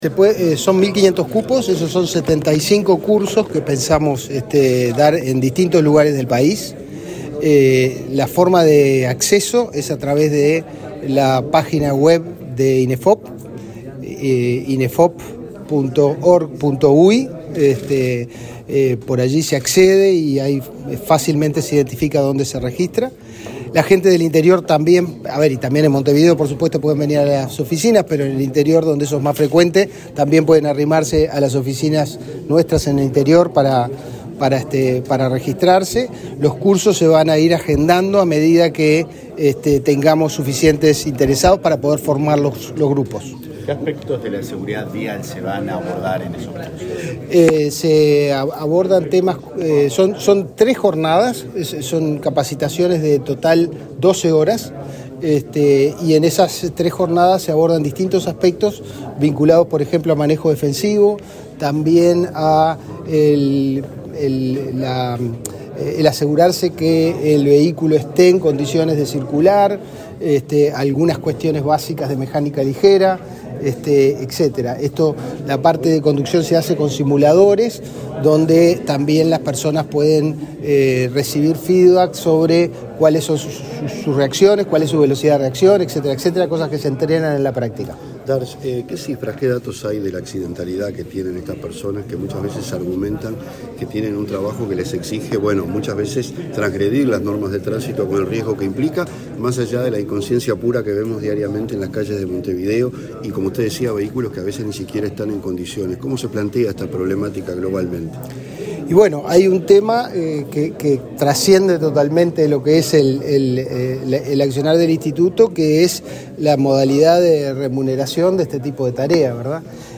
Declaraciones del director de Inefop a la prensa
El inspector general de Trabajo, Tomás Teijeiro; el director de la Unidad Nacional de Seguridad Vial (Unasev), Mauricio Viera, y el director general del Instituto Nacional de Empleo y Formación Profesional (Inefop), Pablo Darscht, participaron en el lanzamiento de capacitaciones sobre seguridad vial para repartidores en motocicleta y bicicleta. Luego Darscht dialogó con la prensa.